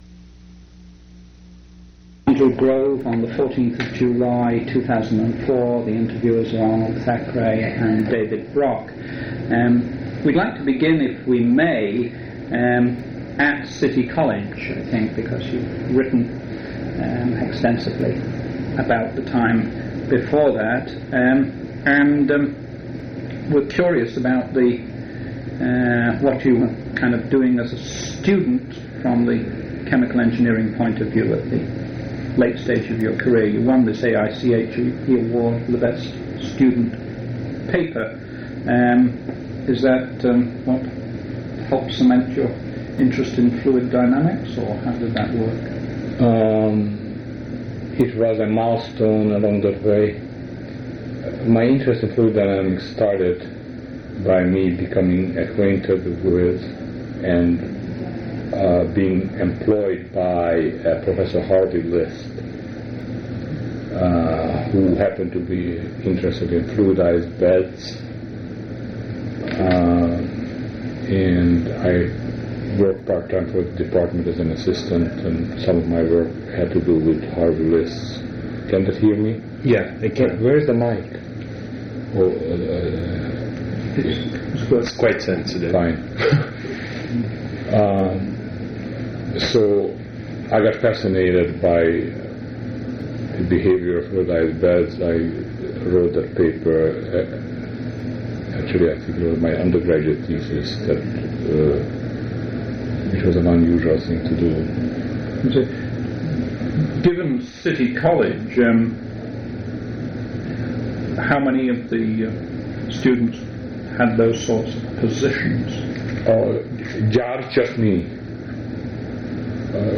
Oral history interview with Andrew S. Grove